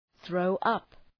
throw-up.mp3